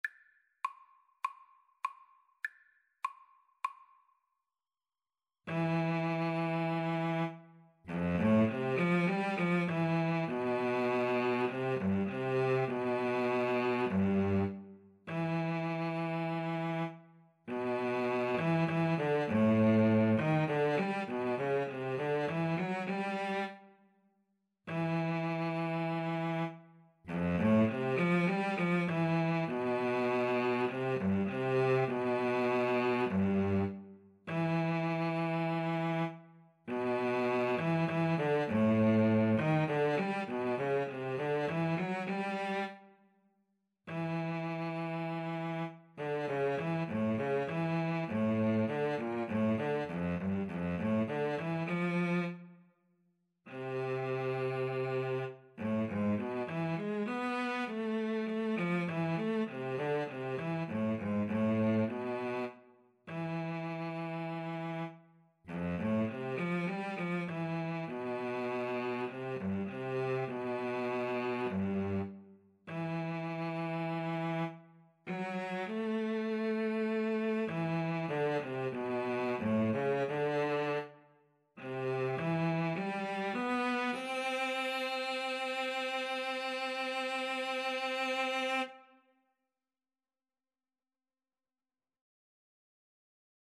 Classical (View more Classical Clarinet-Cello Duet Music)